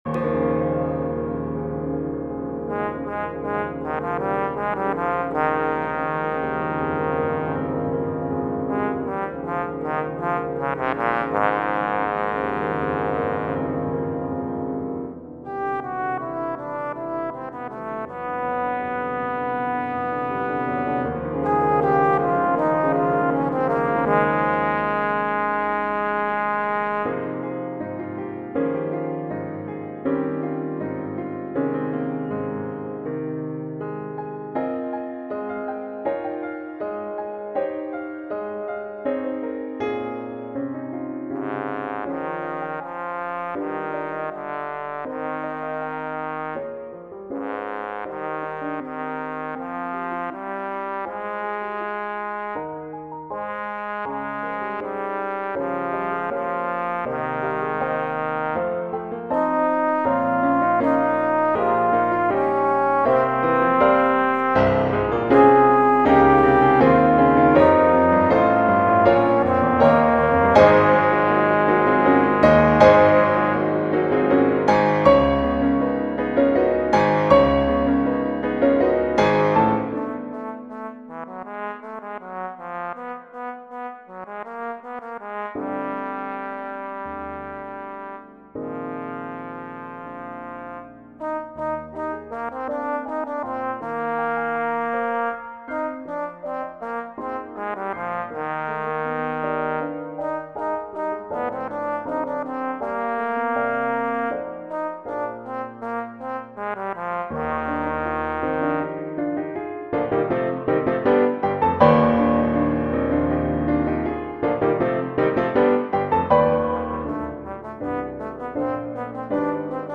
Difficulty: Advanced
arranged for Trombone and Piano.